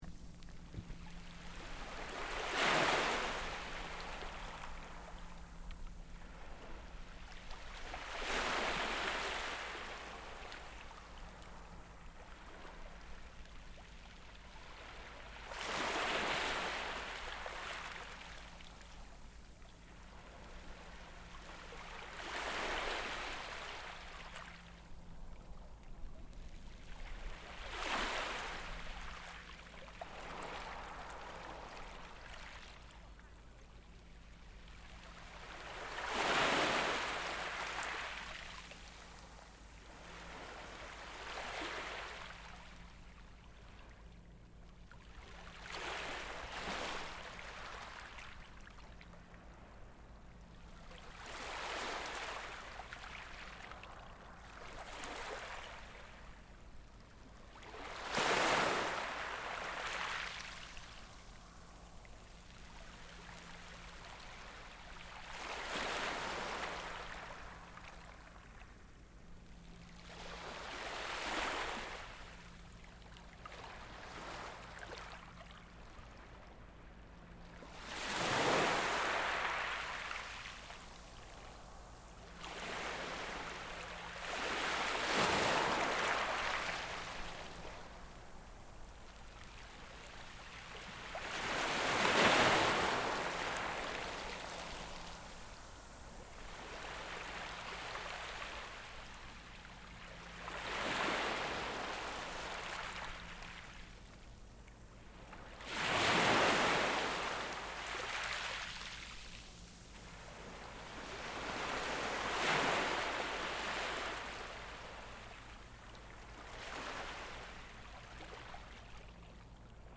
Waves on Cromarty beach, Scotland